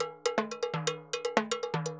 Timbaleta_Salsa 120_1.wav